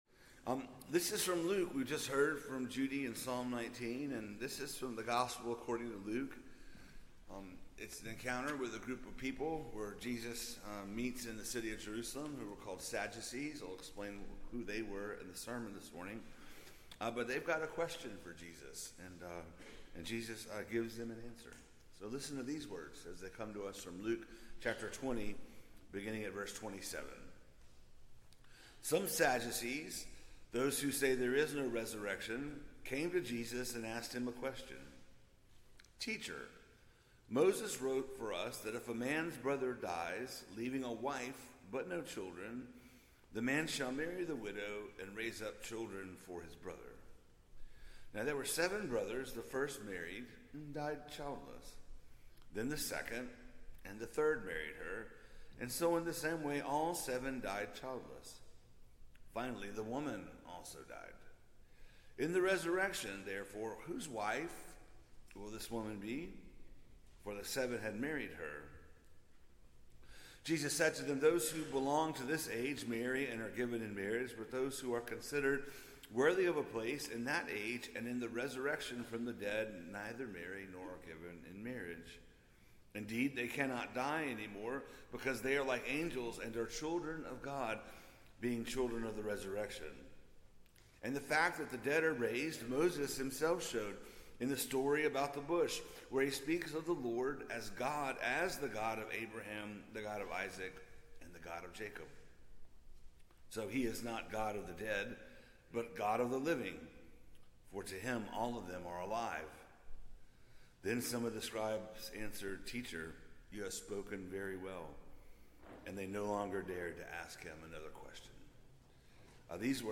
Children of God | White Memorial Presbyterian Church